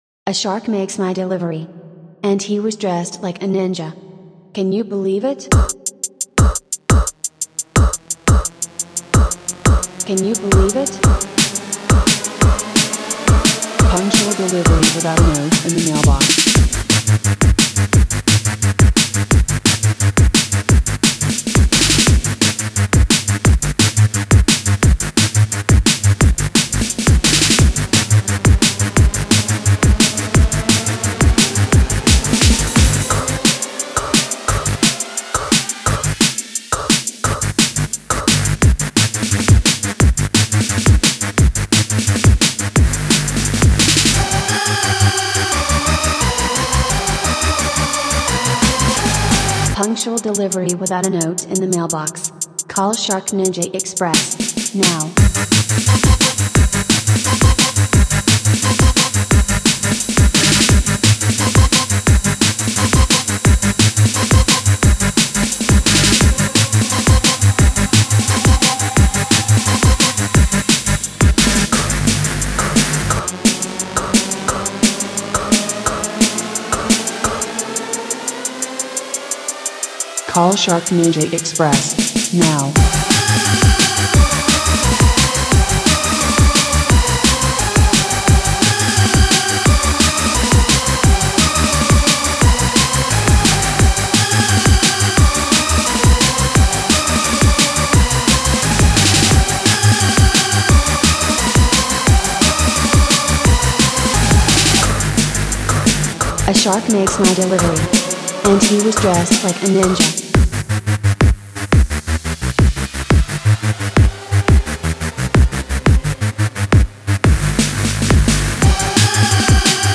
EBM, Industrial, Dark Electro, Cyberindustrial, dark techno